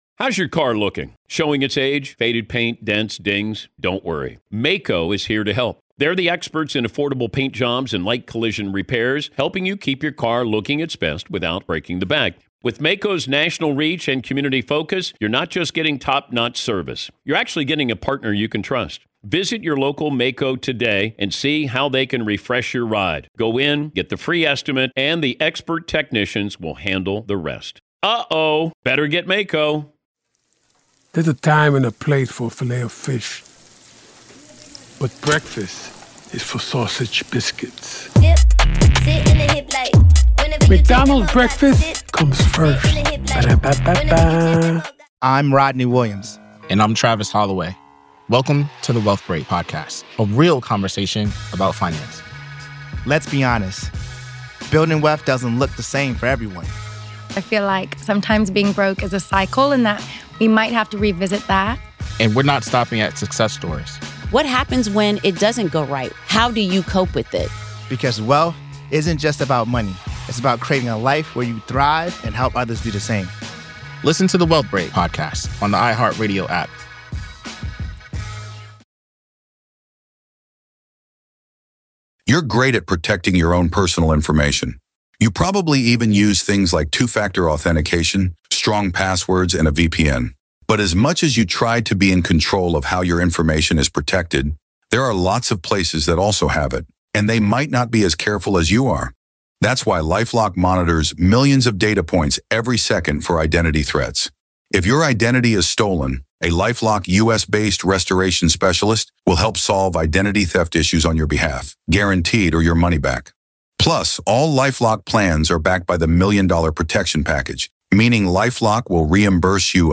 Daily True Crime News & Interviews